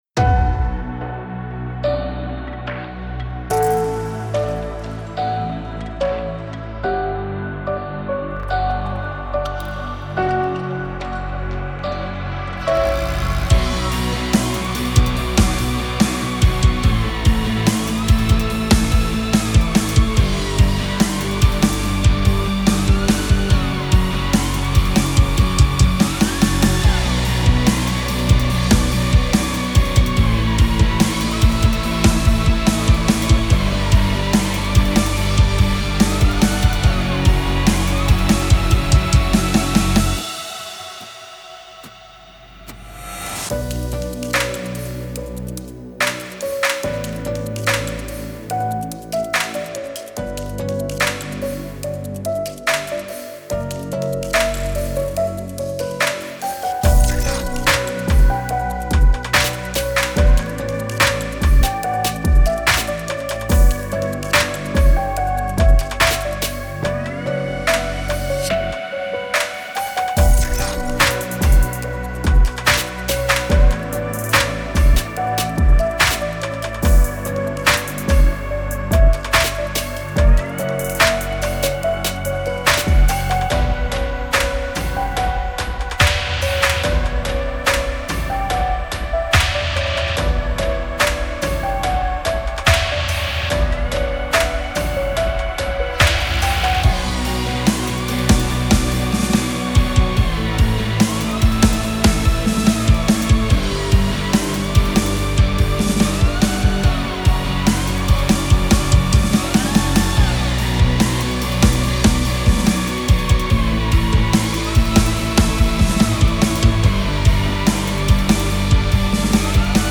| RapCore
Цель баланса - сделать жирный рэпкор припев, слегка вязкий атмосферный куплет, но с отчетливой ударкой, и при этом так - чтобы не звучало как не сбалансированная каловая масса, и не было перегружено, поскольку это все-таки бит, а не полноценный инструментал. Сам почему-то не понимаю, что делать.